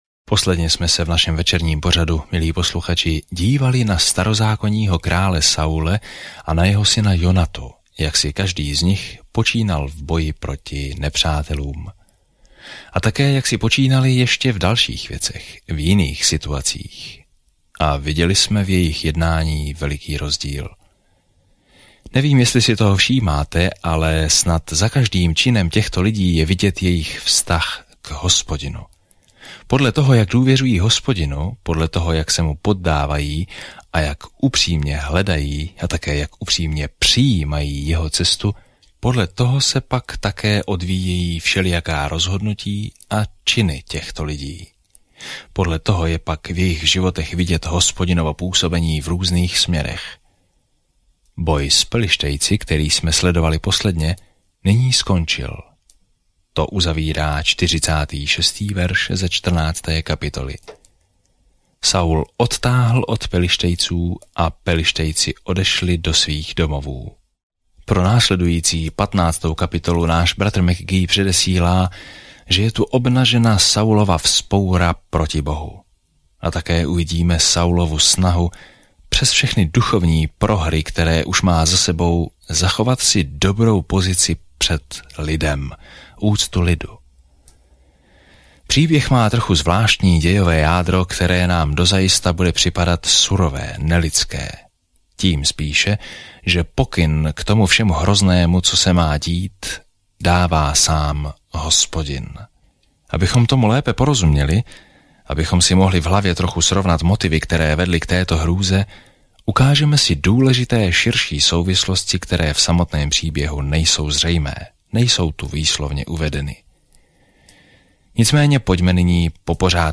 Písmo 1 Samuel 15 Den 15 Začít tento plán Den 17 O tomto plánu Nejprve Samuel začíná Bohem jako izraelským králem a pokračuje v příběhu o tom, jak se Saul a poté David stal králem. Denně procházejte Prvním Samuelem, zatímco budete poslouchat audiostudii a číst vybrané verše z Božího slova.